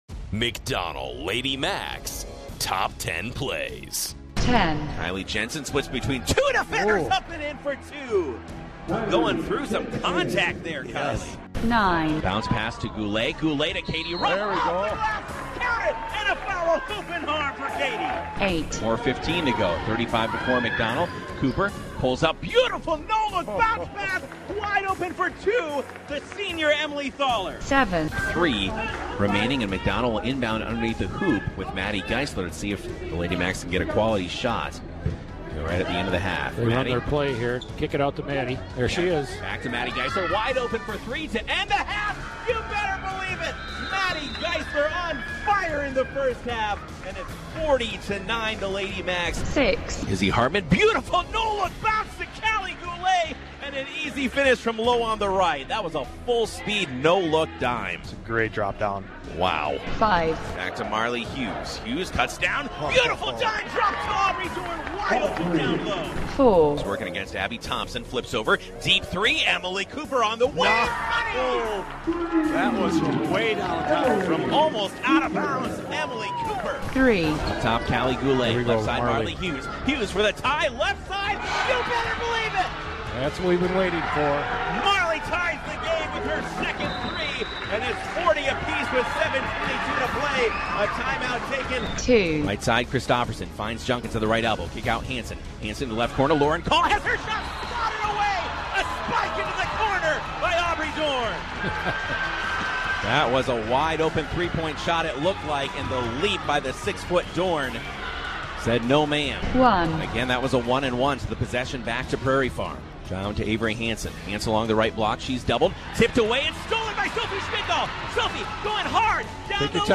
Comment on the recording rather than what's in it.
Another year of exciting McDonell Lady Macks basketball on WOGO!